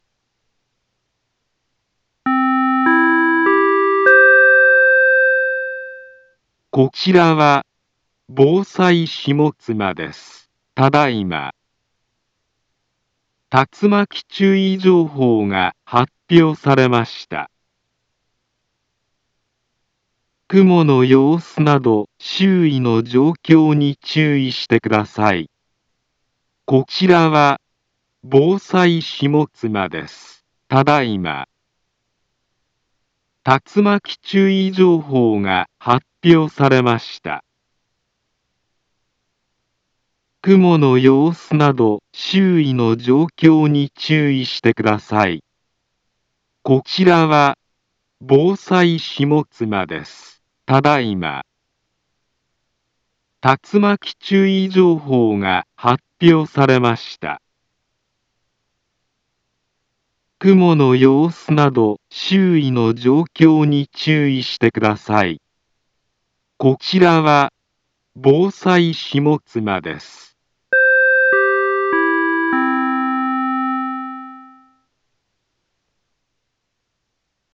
Back Home Ｊアラート情報 音声放送 再生 災害情報 カテゴリ：J-ALERT 登録日時：2024-07-30 16:54:31 インフォメーション：茨城県北部、南部は、竜巻などの激しい突風が発生しやすい気象状況になっています。